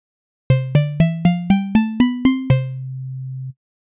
42_Synth-05.wav